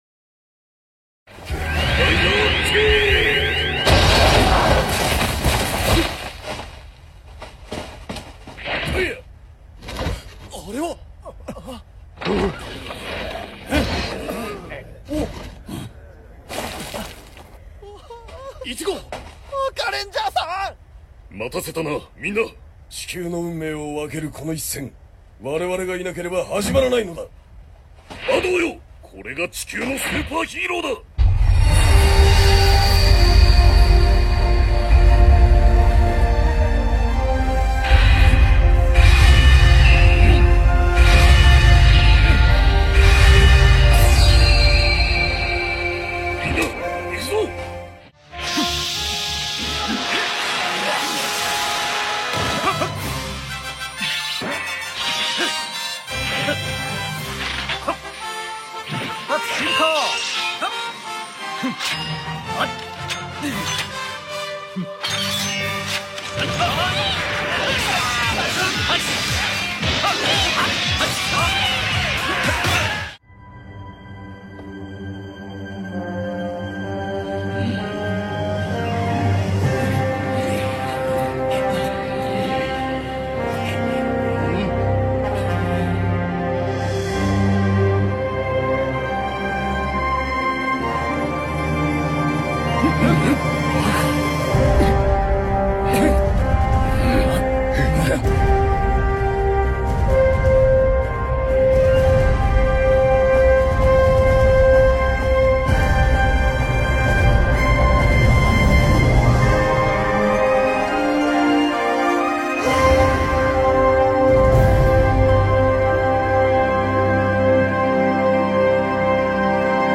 All Assemblies In Kamen Rider Sound Effects Free Download